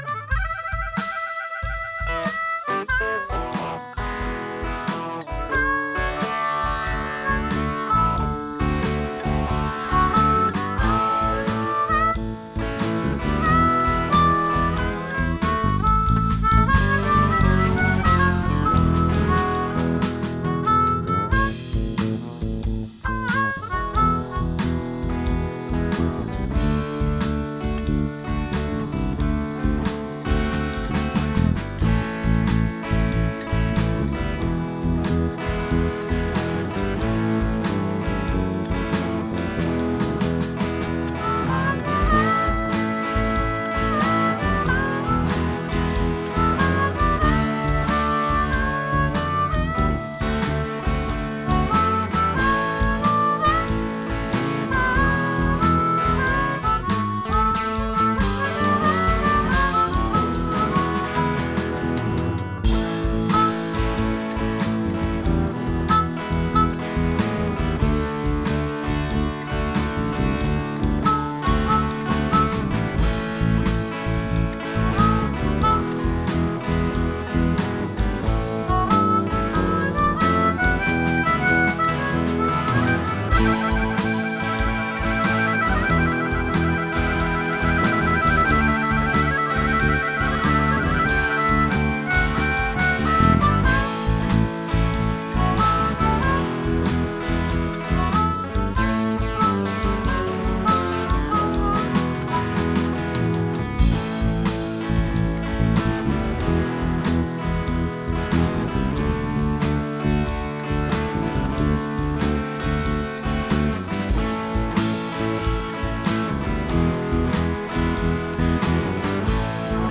Jam session
Guitars
Bass
Drums
Harmonica